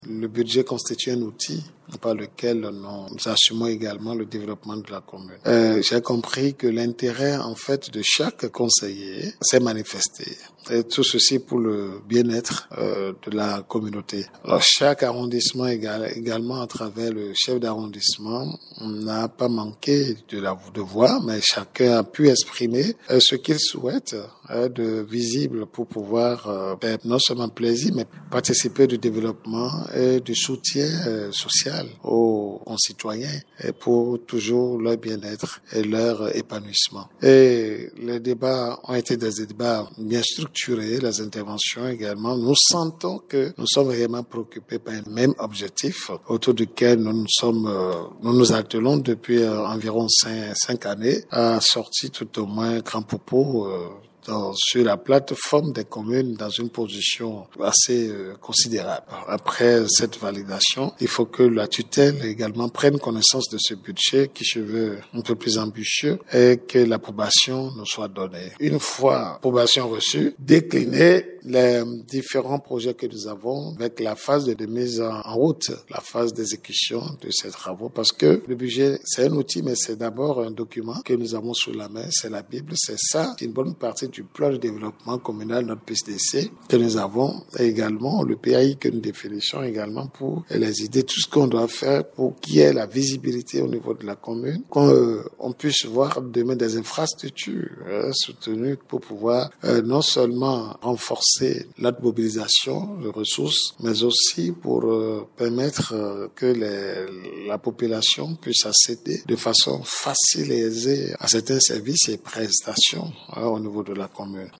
L’édile de la Cité des Xwla a salué l’engagement de l’ensemble des membres du conseil communal pour le développement de la commune . Jocelyn Ahyi , maire de la commune de Grand-Popo est au micro